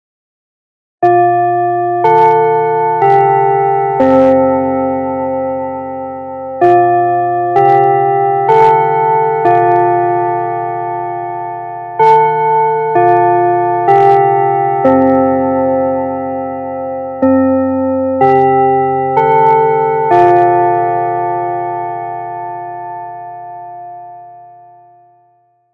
最後のチャイムの時間です。 ６年生のみなさん卒業おめでとうございます。